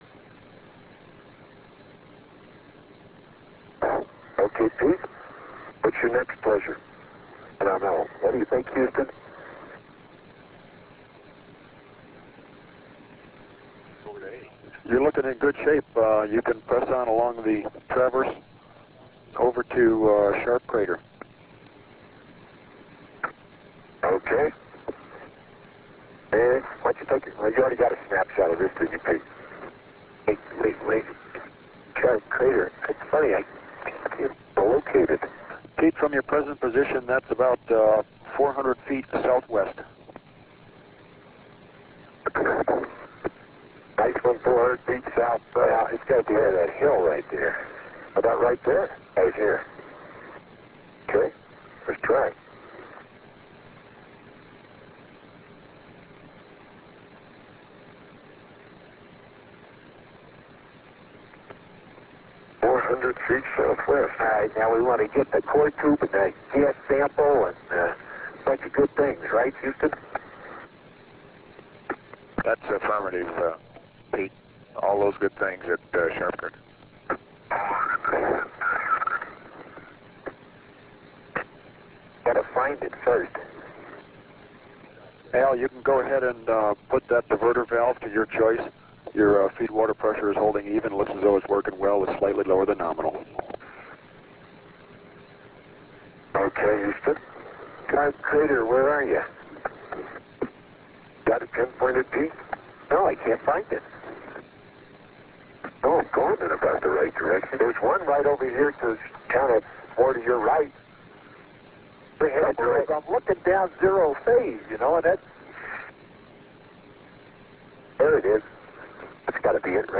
RealAudio Clip ( 24 min 03 sec ) Note that there is a blank section starting at 132:55:14 and lasting for 5 min 50 seconds. This may represent a switch from one tape to another, perhaps during production of the cassette copies used for the ALSJ. When audio resumes, we are still at 132:55:14, so none of the transmissions are missed.